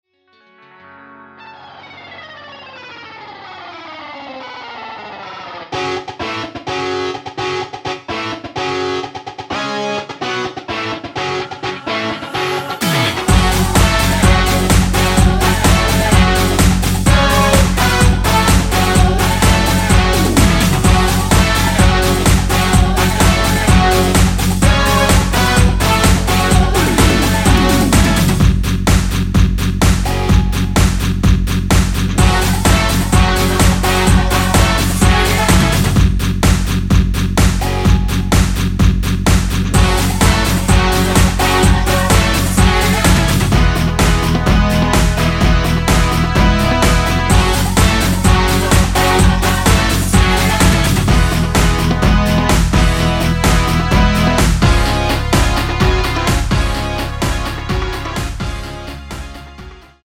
원키에서(-1)내린 코러스 포함된 MR입니다.(미리듣기 참조)
F#m
앞부분30초, 뒷부분30초씩 편집해서 올려 드리고 있습니다.